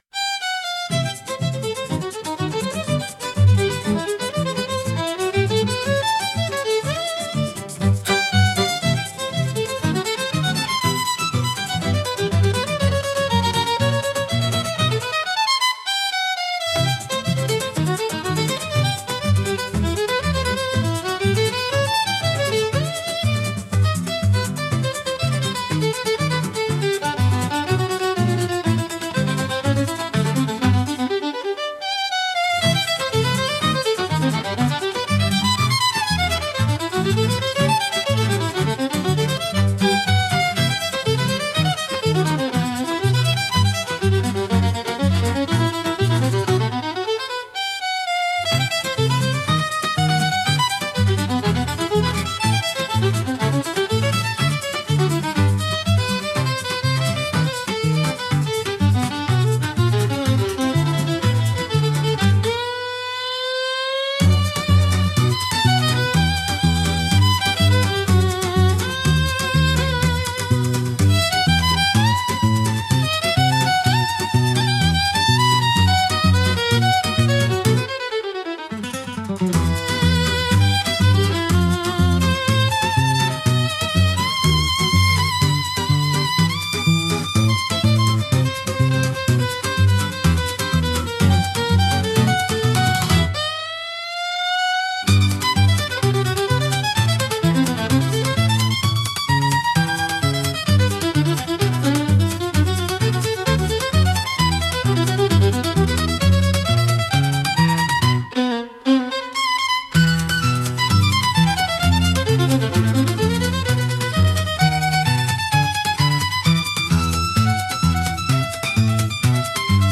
música e arranjo IA) instrumental 5